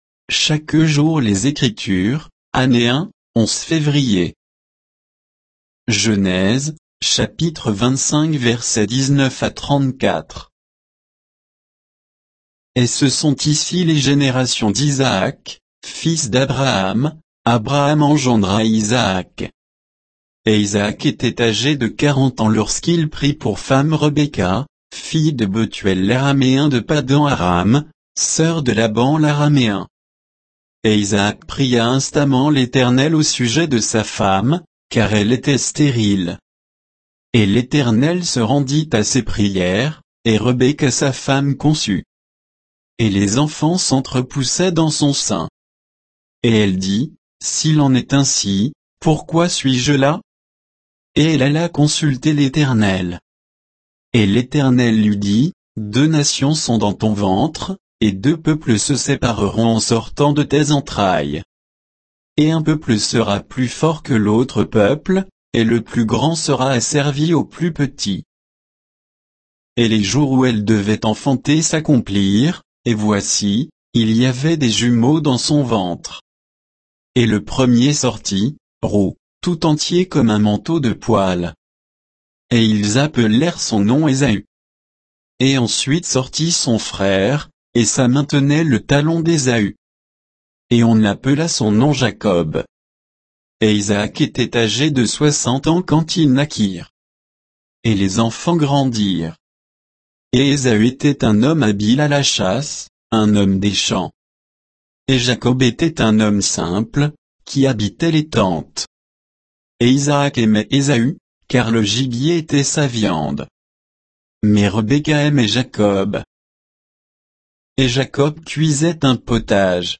Méditation quoditienne de Chaque jour les Écritures sur Genèse 25, 19 à 34